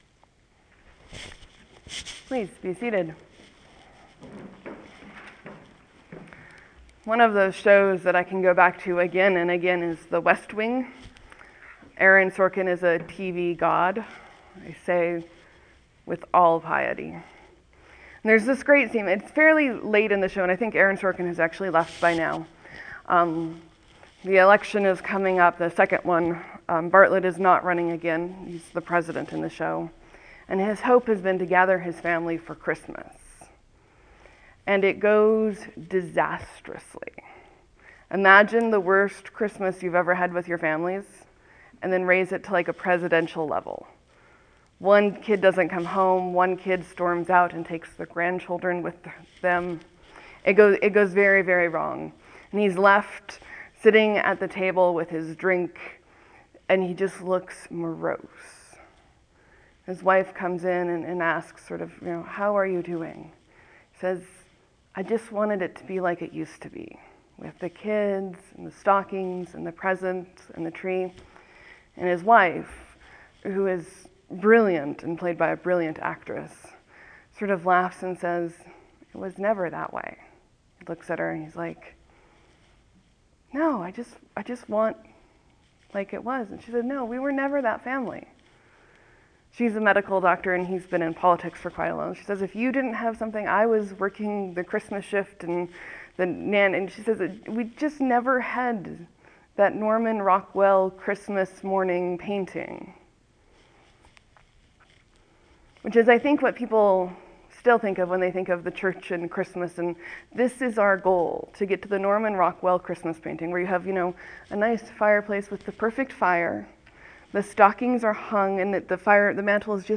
Christmas, Sermon, , , , , , , 2 Comments